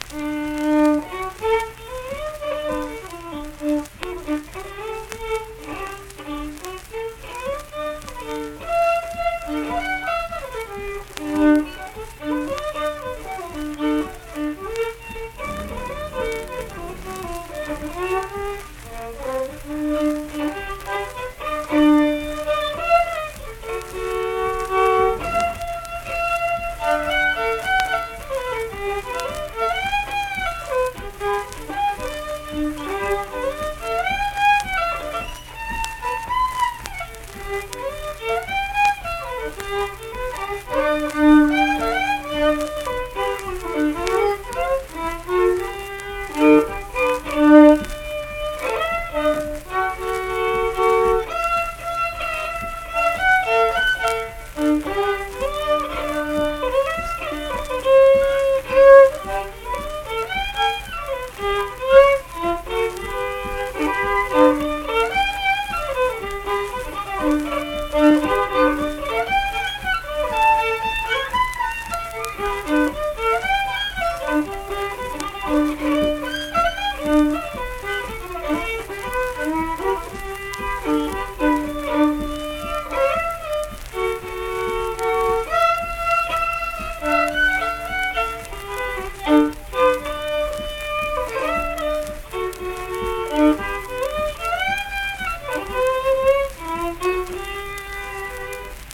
Unaccompanied fiddle performance
Instrumental Music
Fiddle